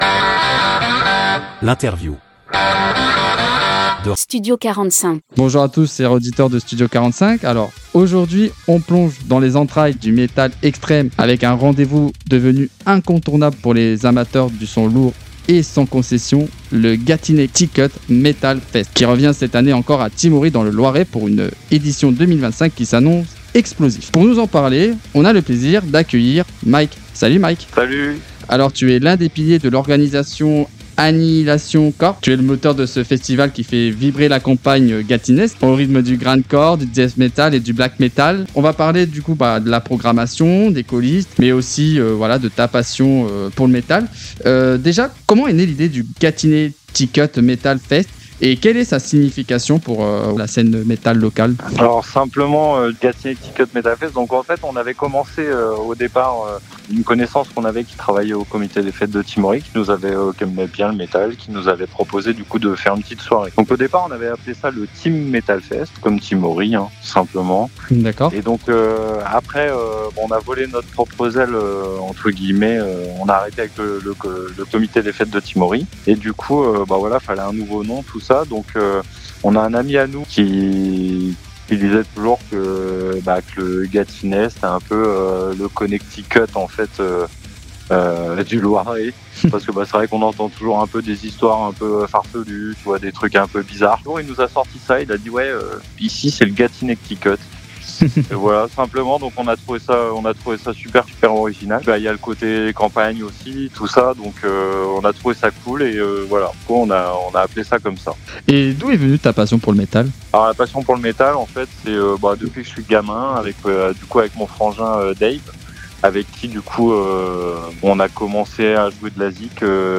Interview Studio 45 - Le Gatinaicticut Metal Fest